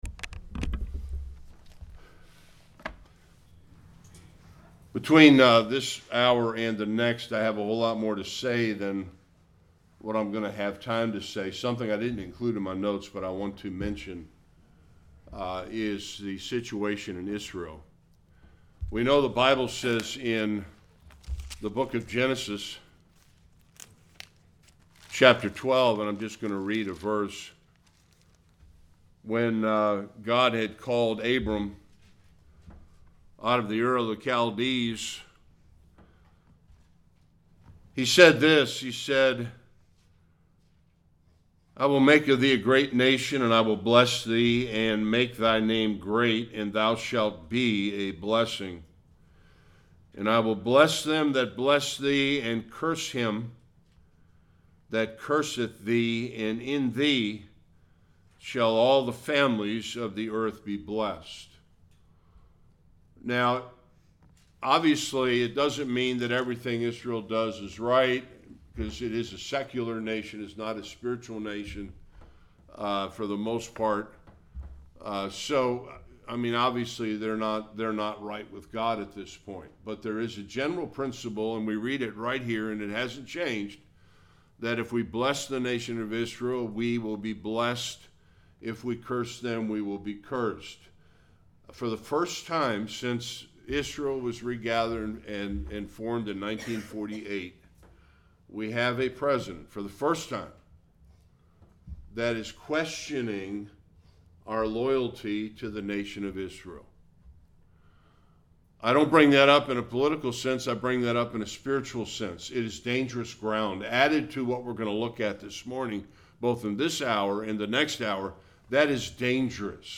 Various Passages Service Type: Sunday School It’s heartbreaking and tragic to see our great nation in decline.